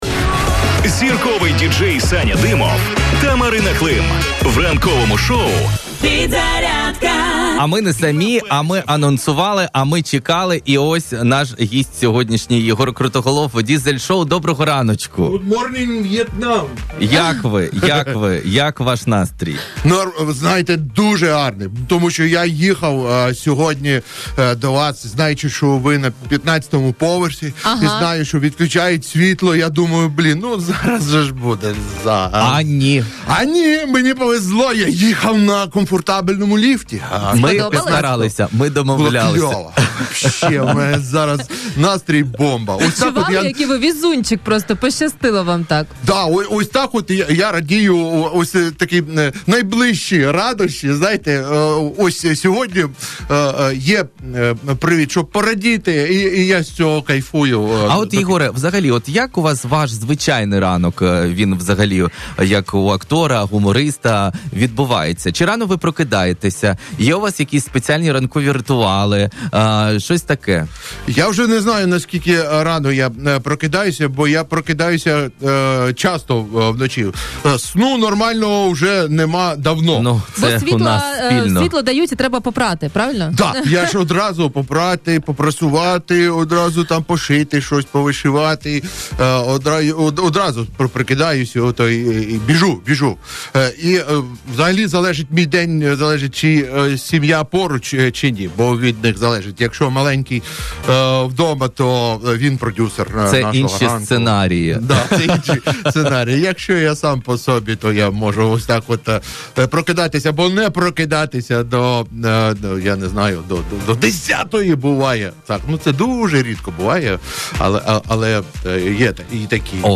Гостем ранкового шоу «Підзарядка» на радіо Перець ФМ був Єгор Крутоголов – телеведучий, актор та художній керівник Дизель Студіо. В ефірі говорили про головну подію для всіх шанувальників гумору – великі концерти «Дизель Шоу», які відбудуться 27 та 28 лютого, а також 1 березня у МЦКМ (Жовтневий палац) у Києві.